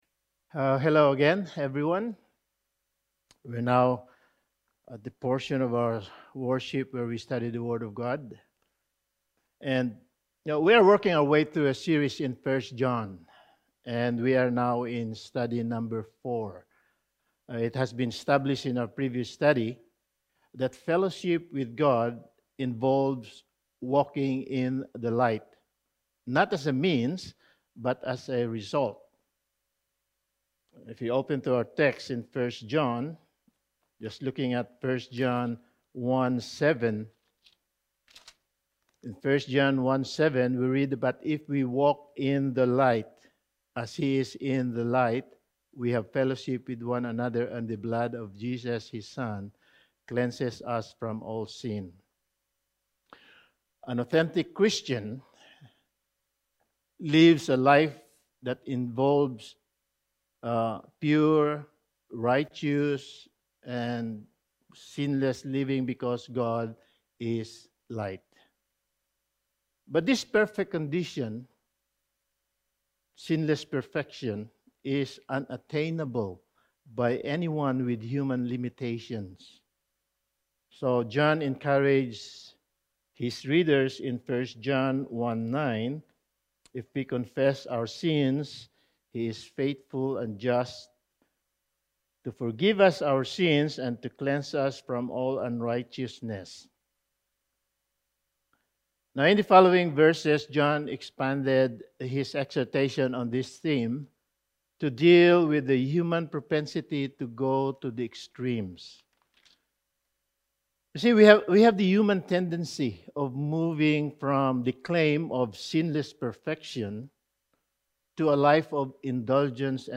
1 John Series – Sermon 4: A Biblical View of Obedience
Service Type: Sunday Morning